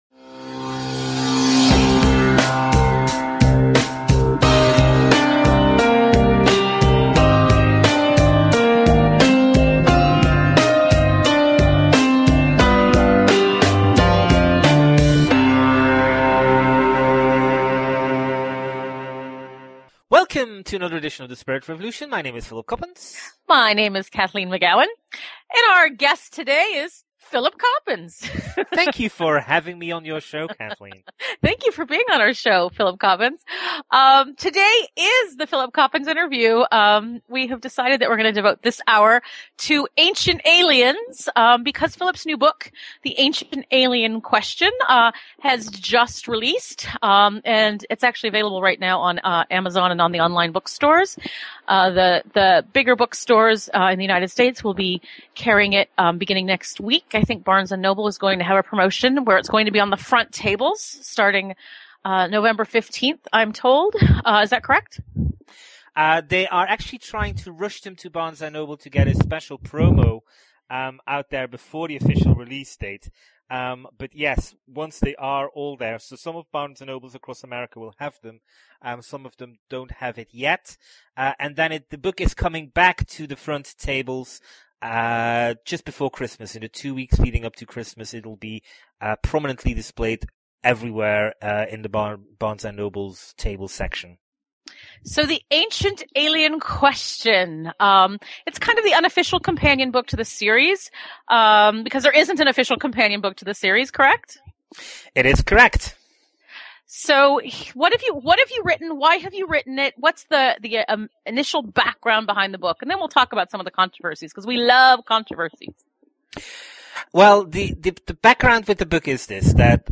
Talk Show Episode, Audio Podcast, The_Spirit_Revolution and Courtesy of BBS Radio on , show guests , about , categorized as
The Spirit Revolution is a weekly one hour radio show, in which Kathleen McGowan and Philip Coppens serve up a riveting cocktail of news, opinion and interviews with leaders in the fields of alternative science, revisionist history and transformational self-help.